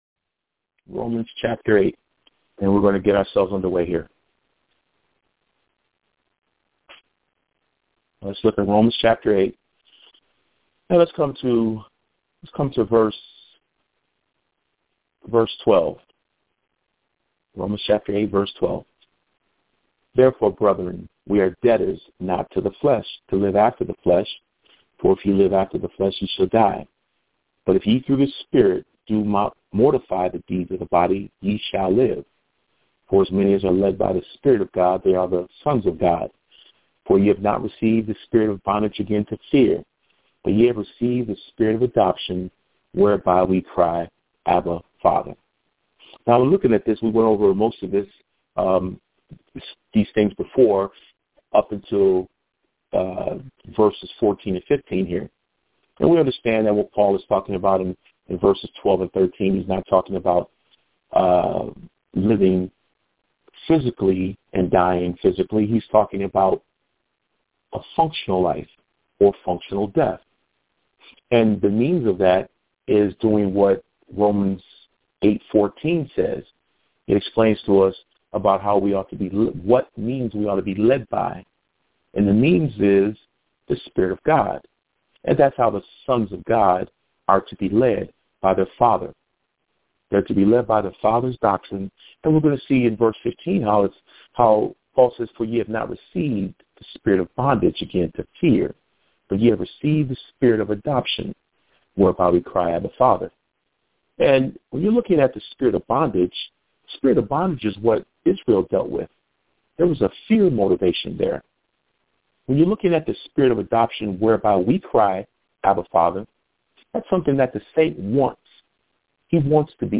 Weekly Phone Bible Studies